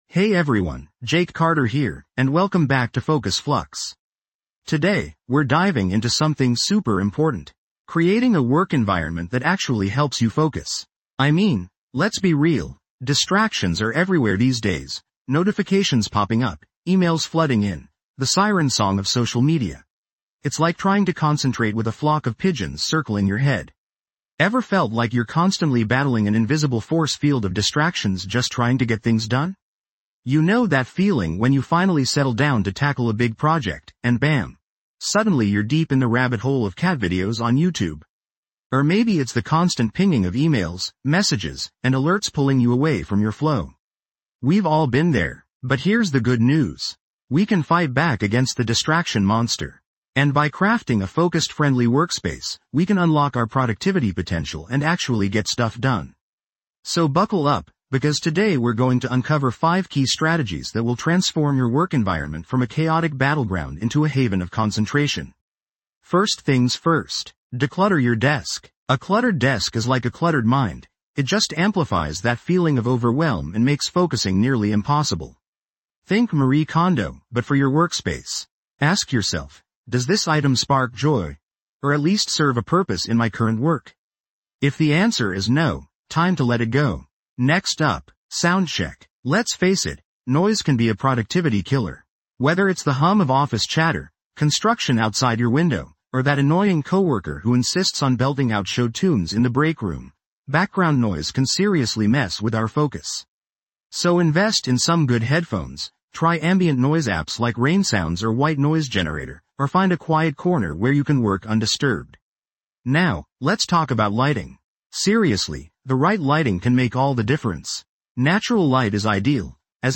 "Focus Flux | ADHD-Like Challenges Explained" delves into the complexities of ADHD and other neurodivergent experiences that impact focus and attention. Through expert interviews, insightful discussions, and relatable stories, this podcast illuminates the unique challenges faced by individuals navigating an often-inattentive world.